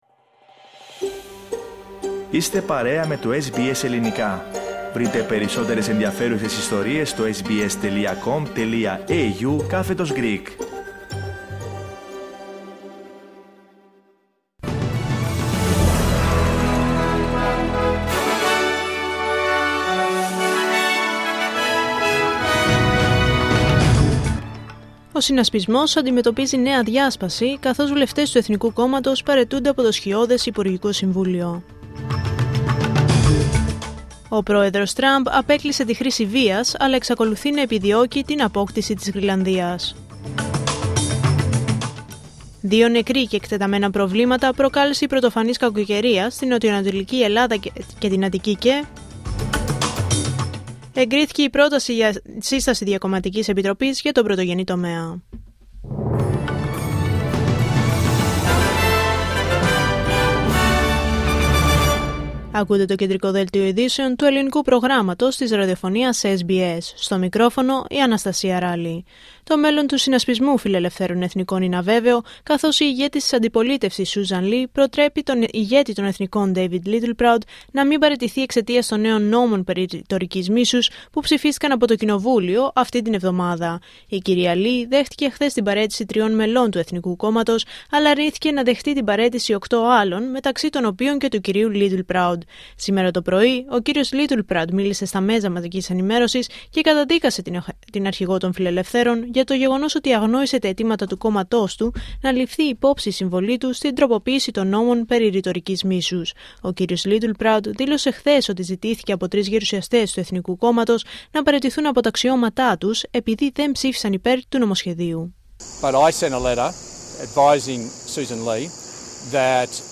Δελτίο Ειδήσεων Πέμπτη 22 Ιανουαρίου 2026